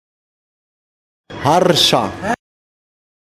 uitspraak 7arscha uitspraak 7arscha voorbeeld Ça, c'est le 7arscha.